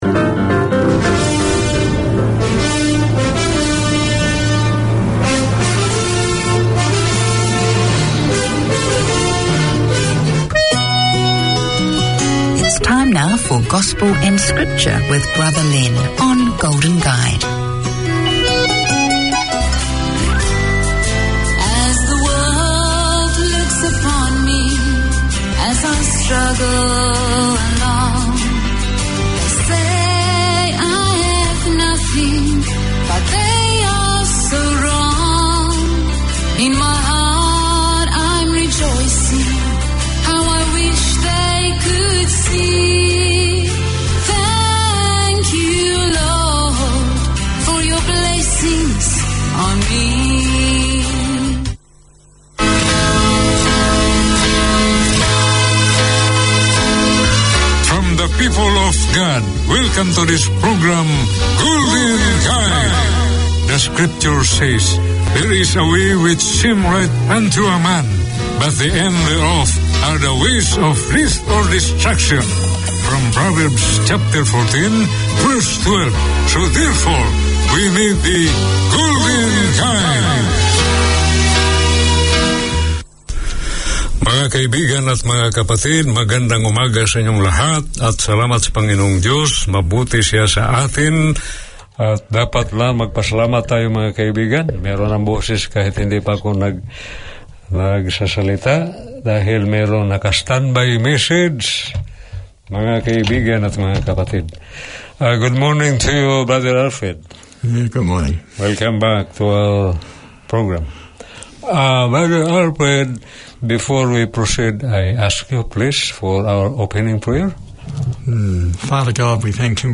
Tune in for scripture and gospel music, as well as occasional guest speakers who share their passion and faith.